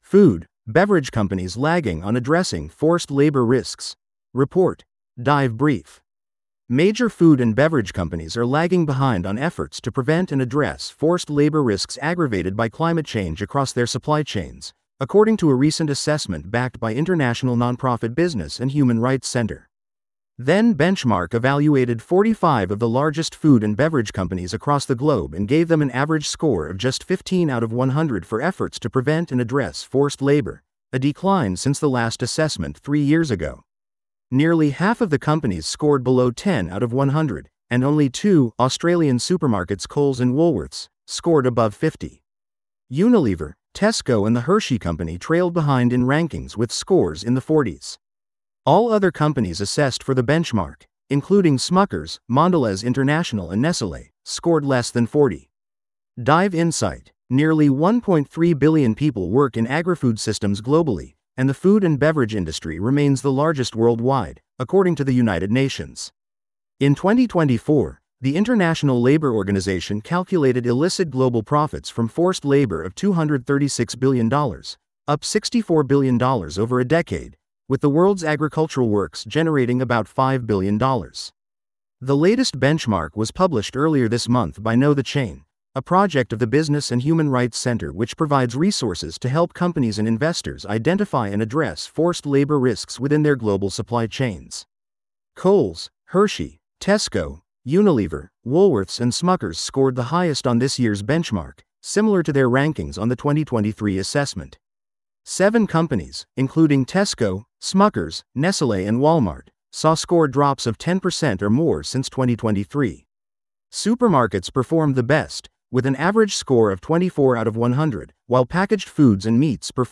This audio is generated automatically.